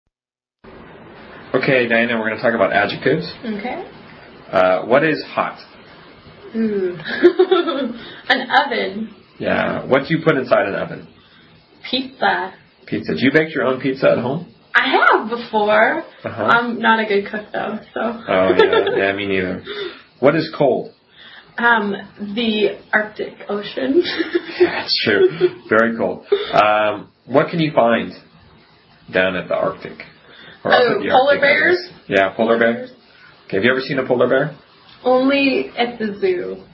英语初级口语对话正常语速16：反义词（mp3+lrc）